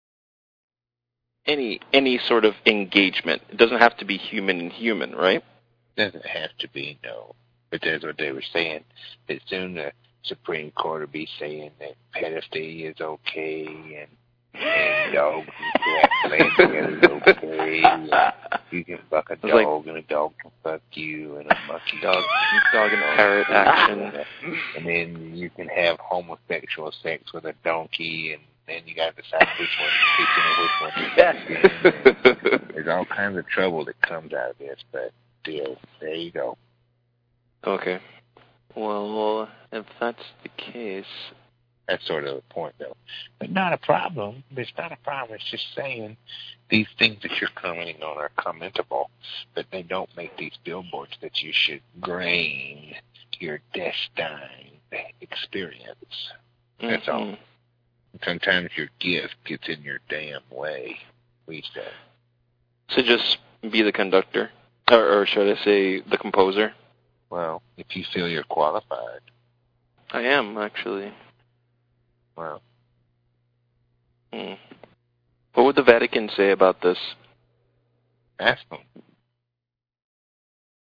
rant on the coming multi-hedonism